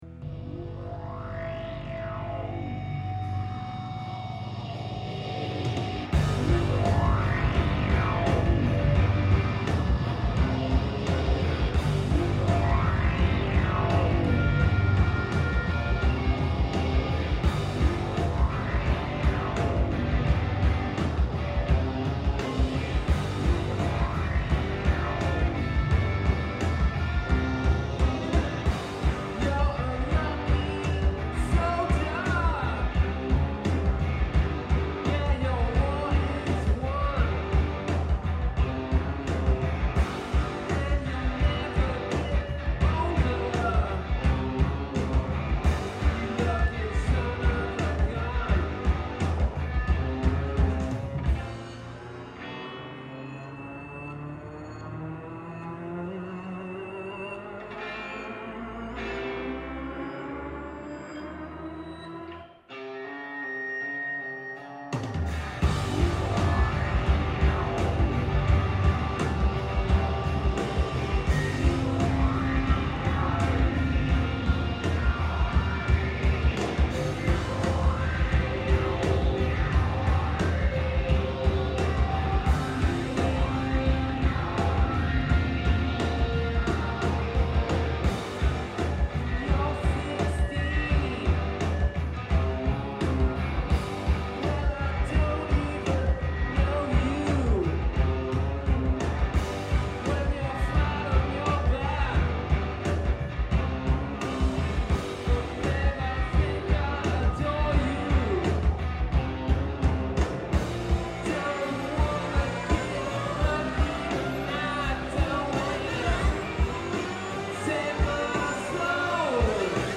drums
keyboards
bass
guitar and vocals